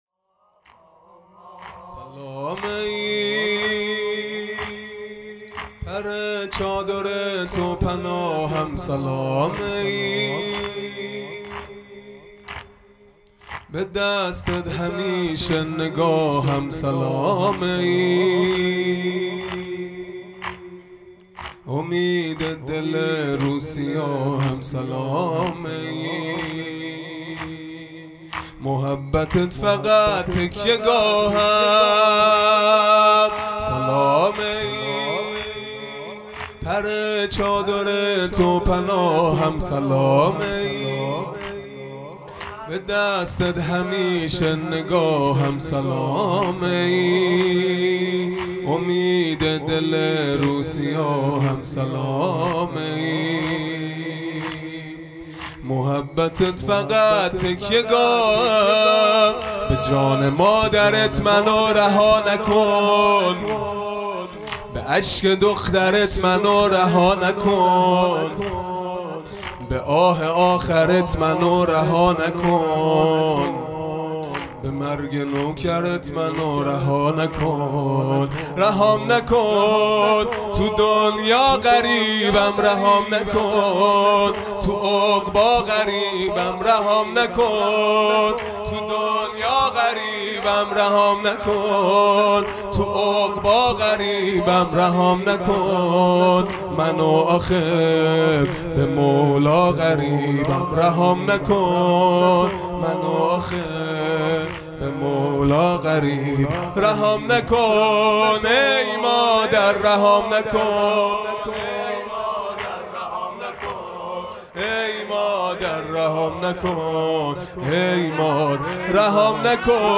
فاطمیه دوم(شب دوم)